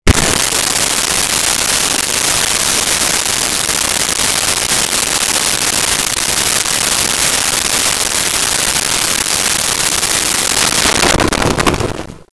Washing Hands 1
washing-hands-1.wav